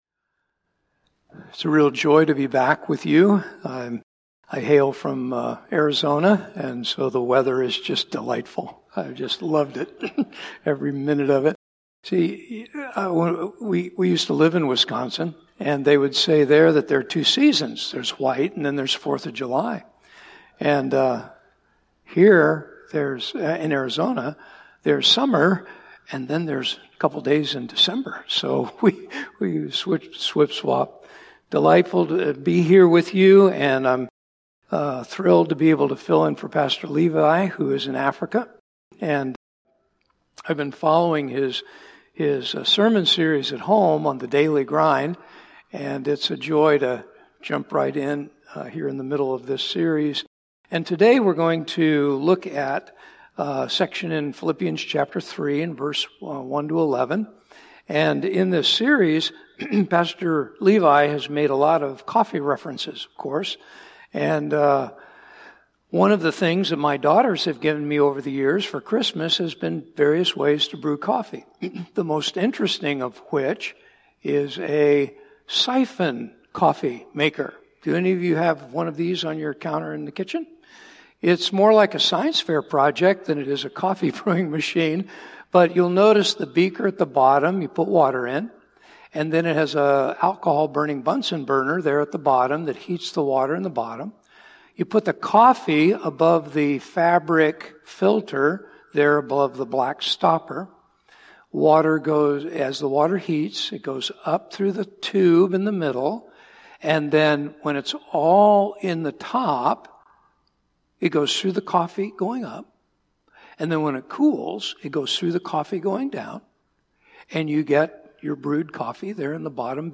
This podcast episode is a Sunday message from Evangel Community Church, Houghton, Michigan, April 6, 2025.